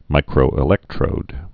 (mīkrō-ĭ-lĕktrōd)